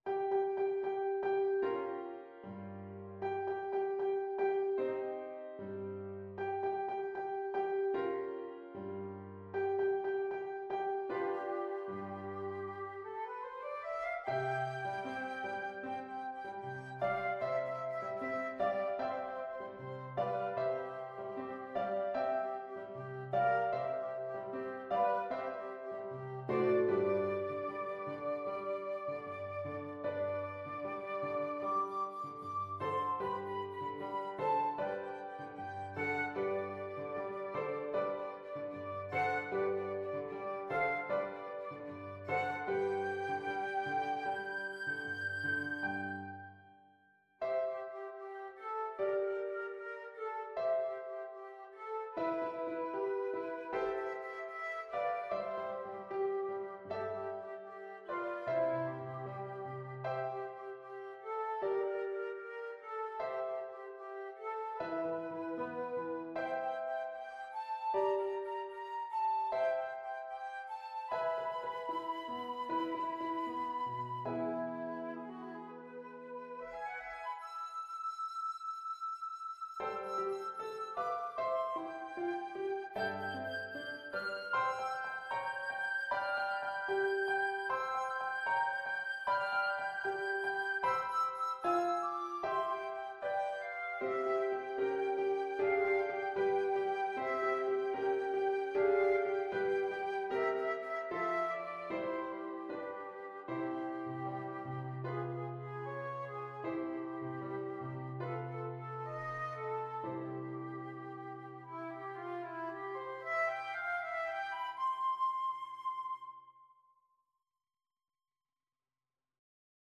Classical Saint-Saëns, Camille Havanaise, Op.83 Flute version
Flute
C major (Sounding Pitch) (View more C major Music for Flute )
=76 Allegretto lusinghiero =104
Classical (View more Classical Flute Music)